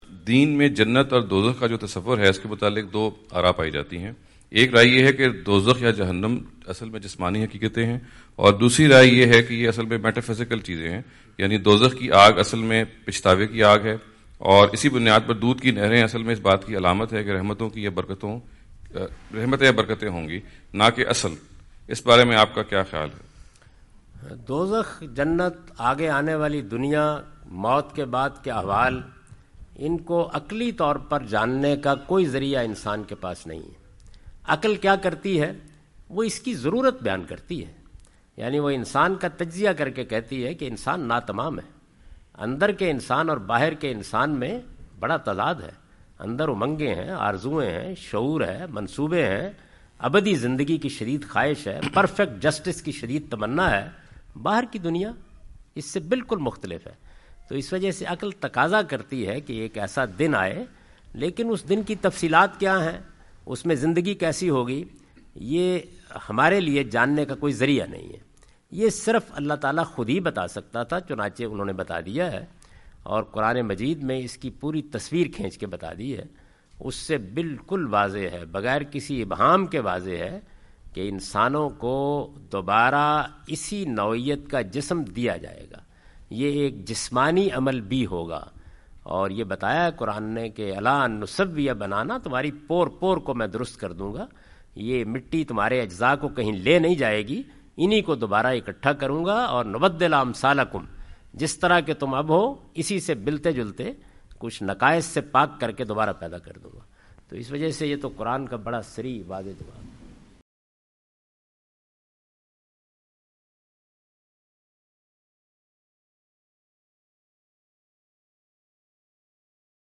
Javed Ahmad Ghamidi answer the question about "Reality of Heaven and Hell" during his US visit.
جاوید احمد غامدی اپنے دورہ امریکہ کے دوران ڈیلس۔ ٹیکساس میں "جنت اور دوزخ کی حقیقت" سے متعلق ایک سوال کا جواب دے رہے ہیں۔